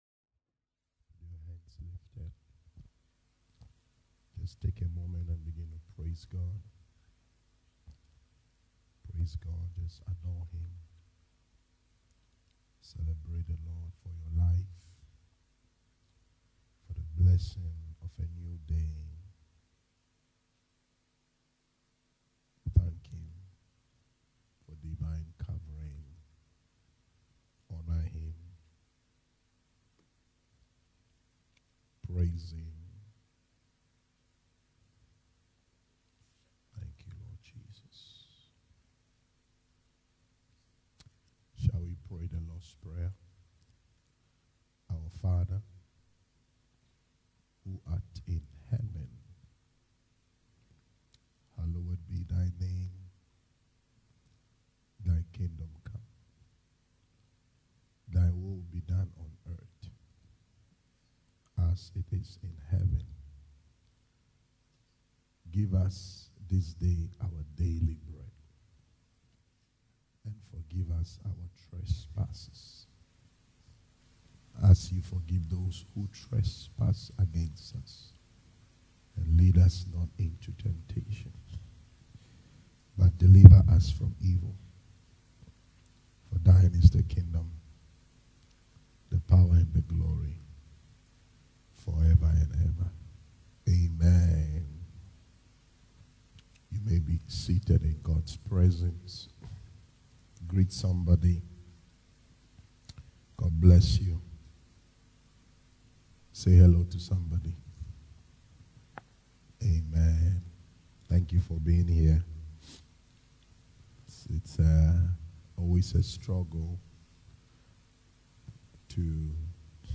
October 8, 2023leadership Teachings, Preaching, Teaching